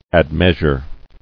[ad·meas·ure]